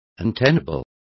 Complete with pronunciation of the translation of untenable.